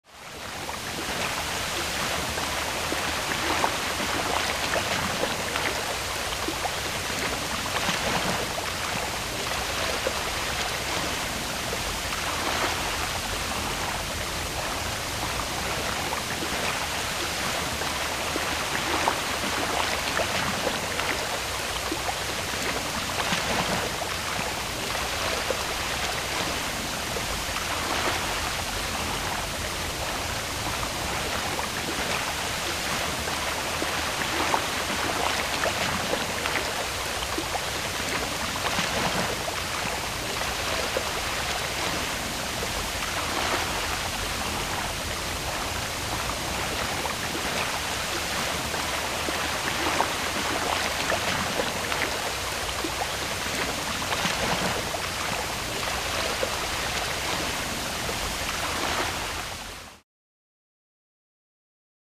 Geyser Bubbles And Hiss, Close Point of View.